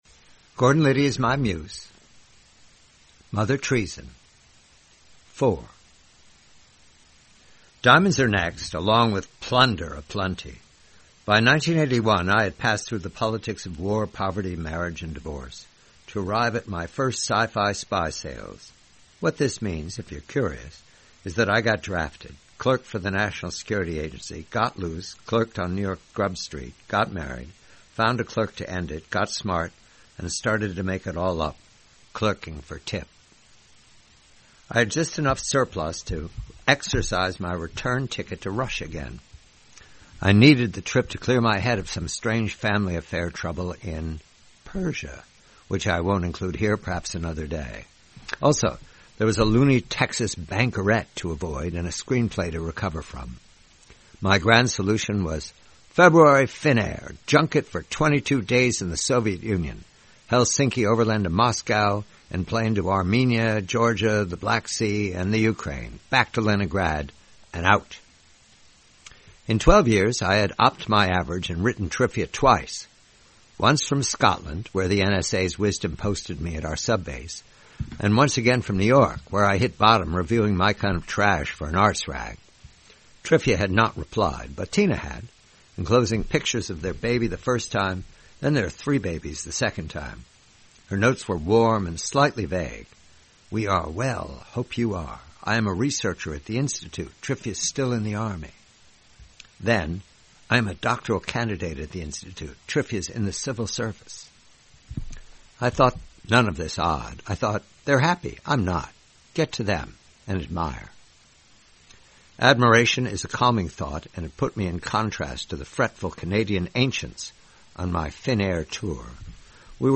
4/7: "Mother Treason," a story from the collection, "Gordon Liddy is My Muse," by John Calvin Batchelor. Read by John Batchelor.